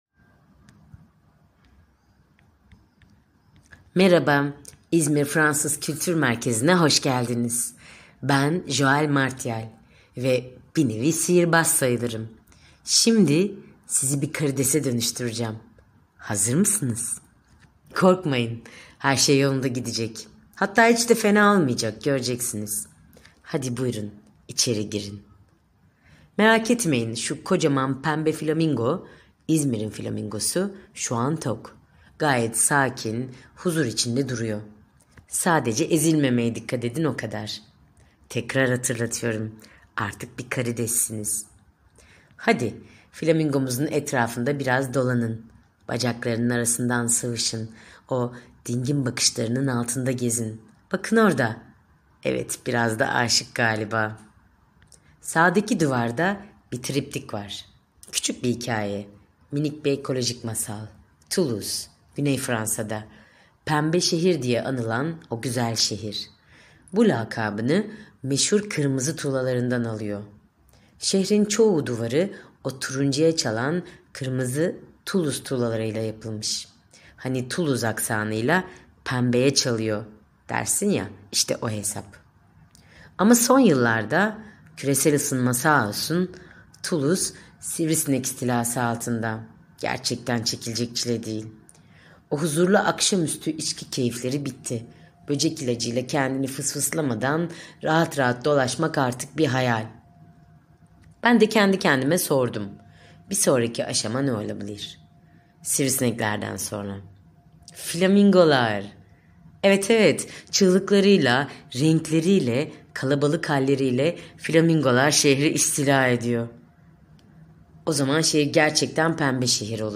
Türkçe seslendirme ile sesli olarak ziyaret edebilirsiniz.